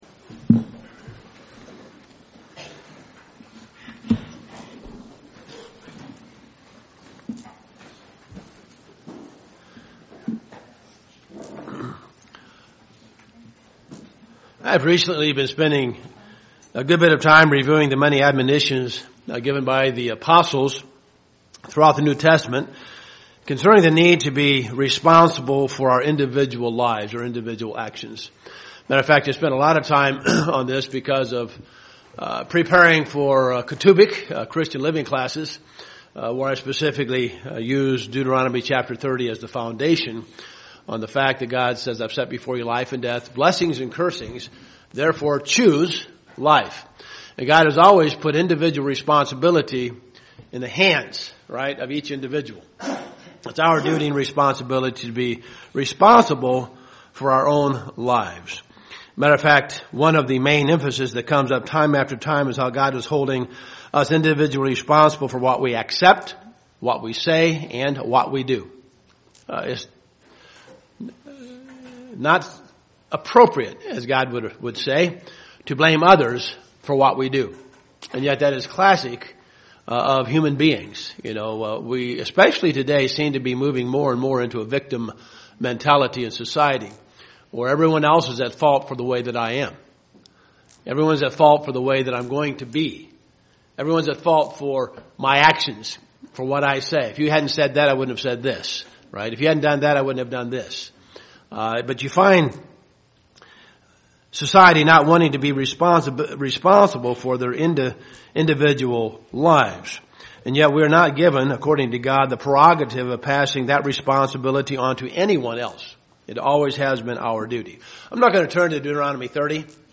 Given in Dayton, OH
Print The actions and things we do in this life have a big impact UCG Sermon Studying the bible?